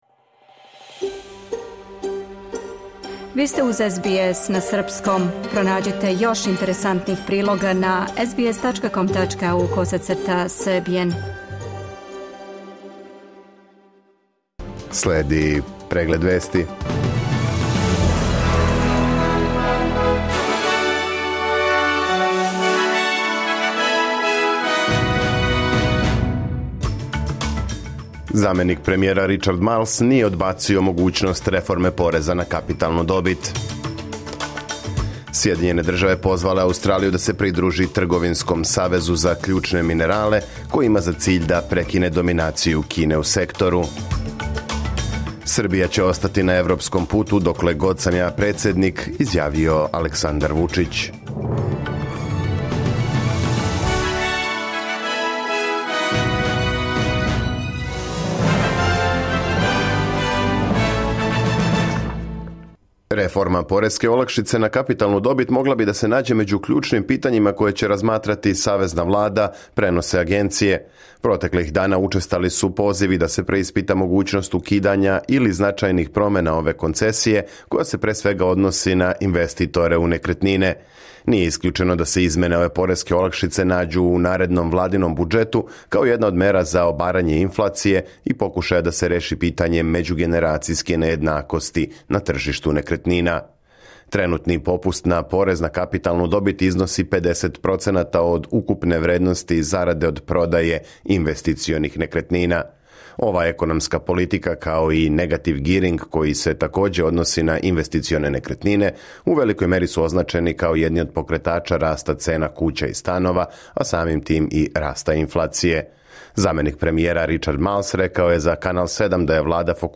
Serbian News Bulletin Source: SBS / SBS Serbian